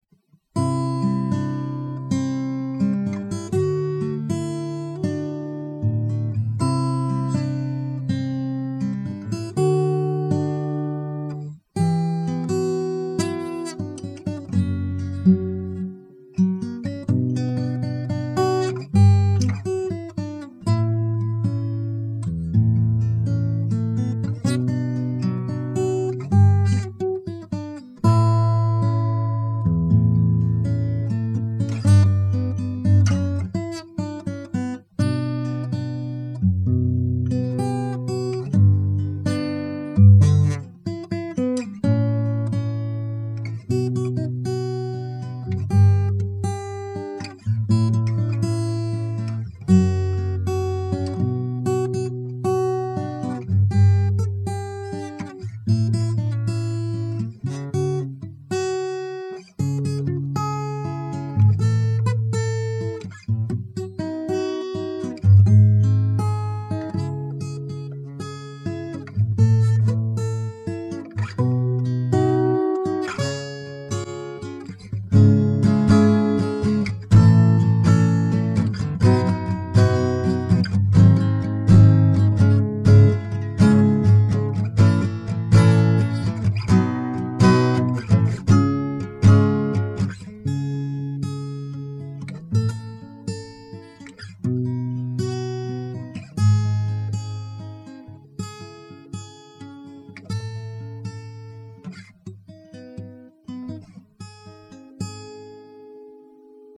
до-диез минор